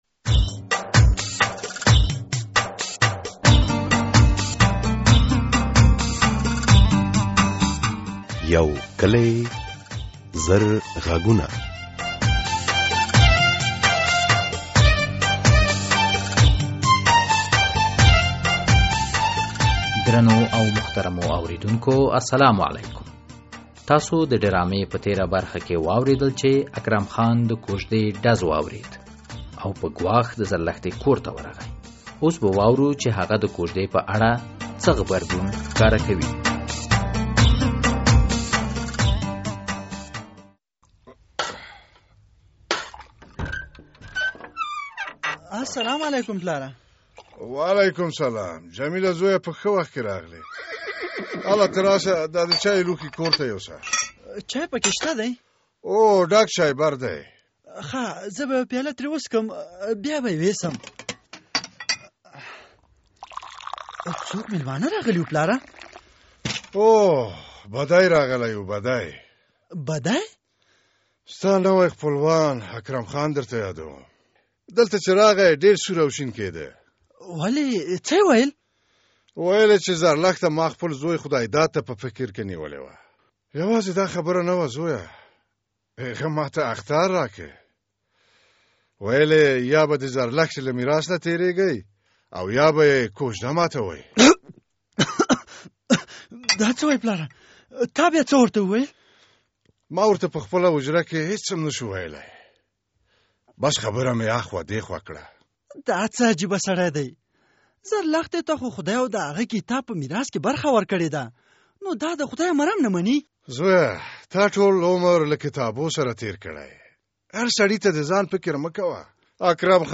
یوکلي او زرغږونه ډرامه هره اونۍ د دوشنبې په ورځ څلور نیمې بجې له ازادي راډیو خپریږي.